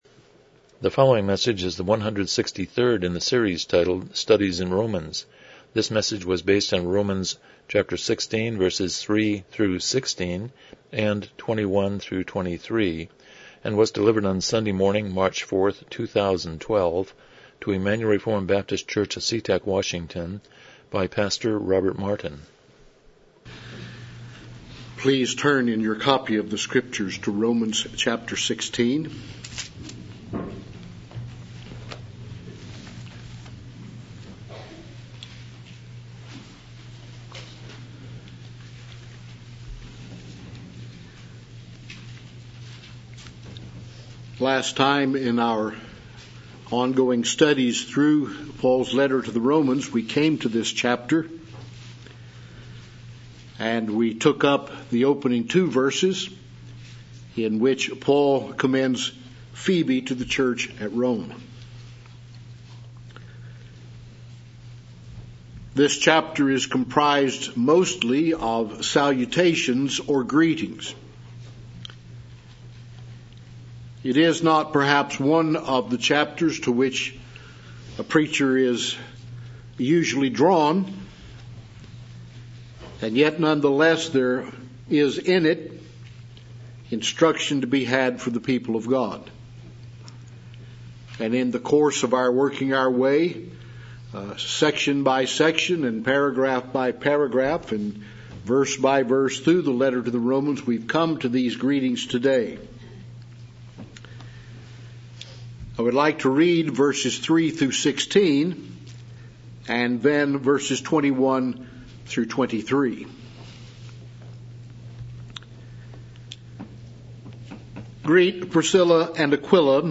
Romans 16:3-23 Service Type: Morning Worship « 141 Chapter 29.2